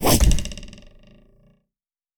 Arrow_1.wav